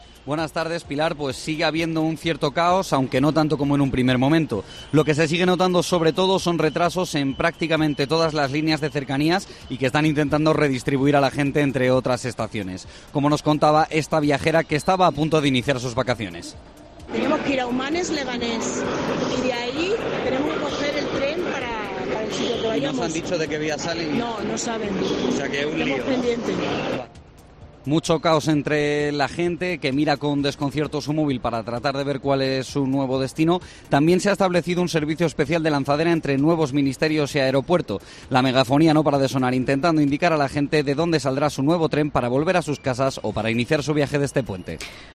desde Atocha